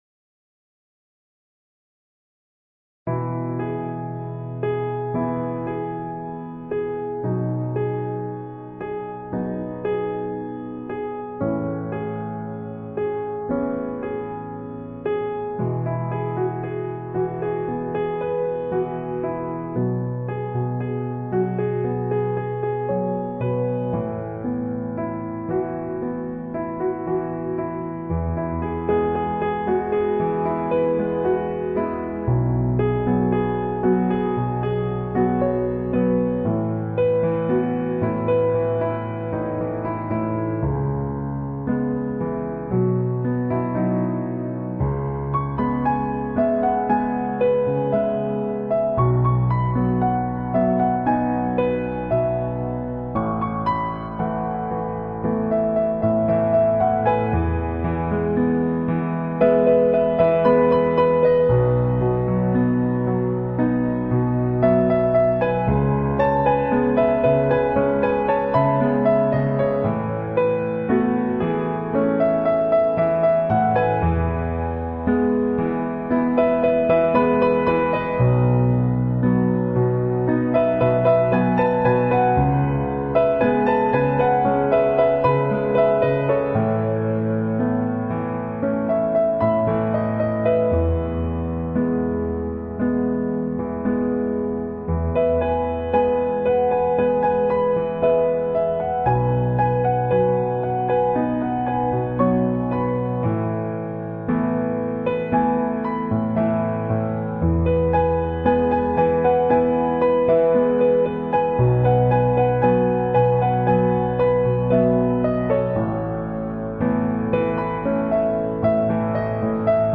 piano cover